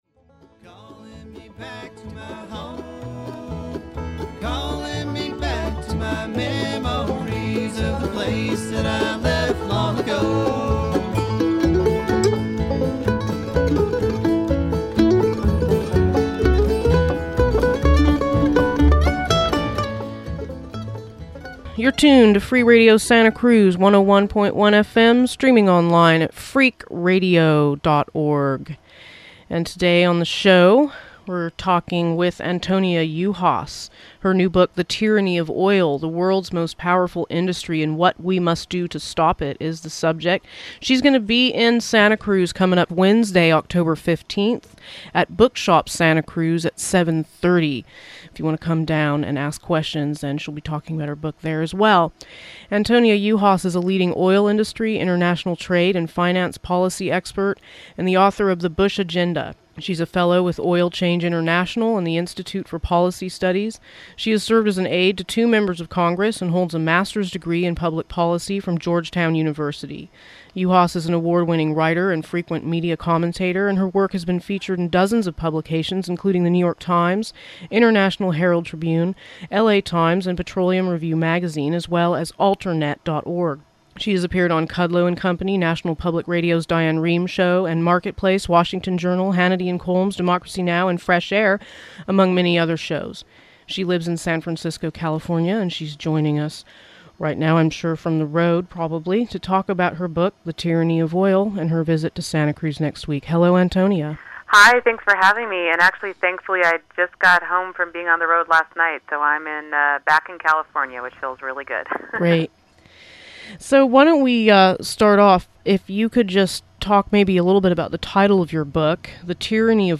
tyranny_of_oil_interview.mp3